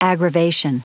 Prononciation du mot aggravation en anglais (fichier audio)
Prononciation du mot : aggravation